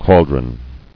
[chal·dron]